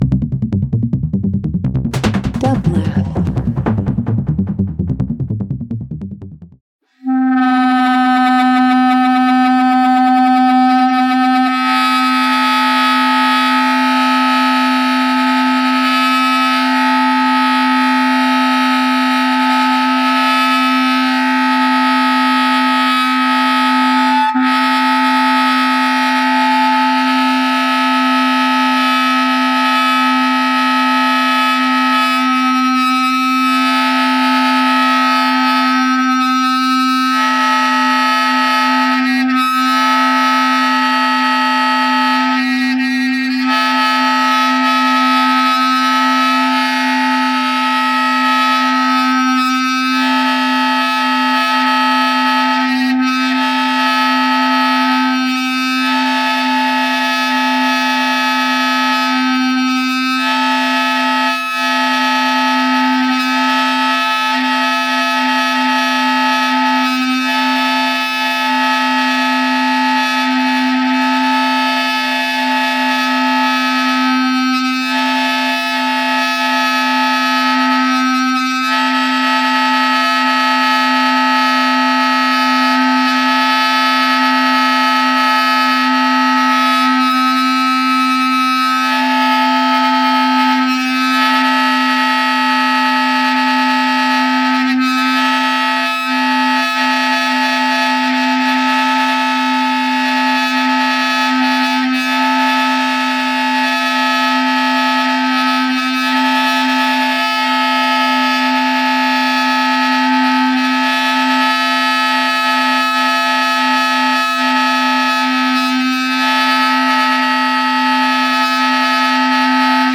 Avant-Garde Jazz Spiritual